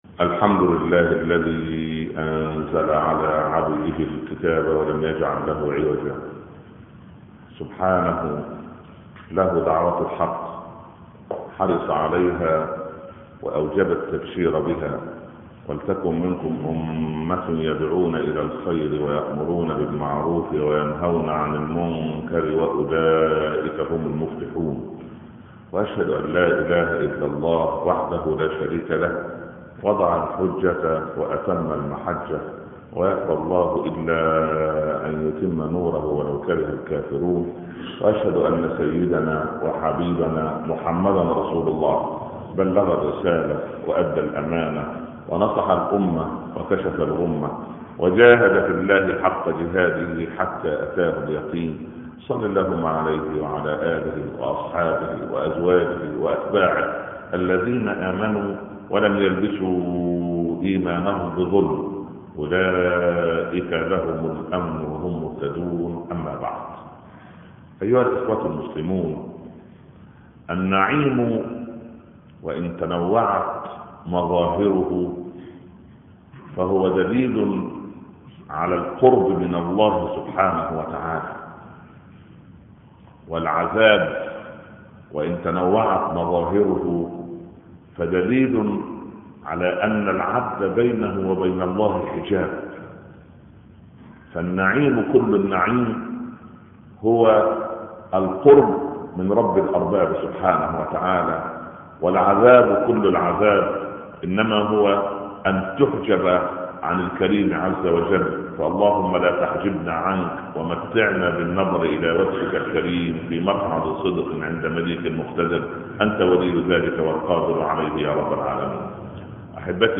khotab-download-87242.htm